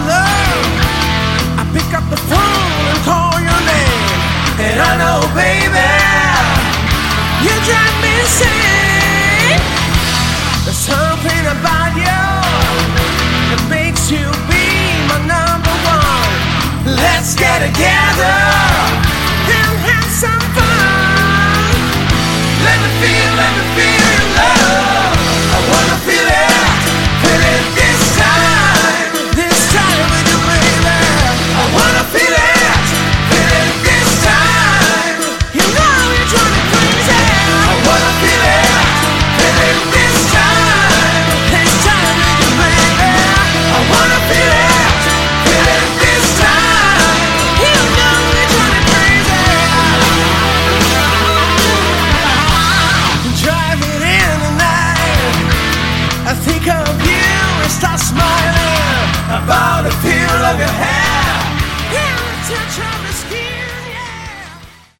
Category: Melodic Hard Rock
guitar, vocals
bass
keyboards
drums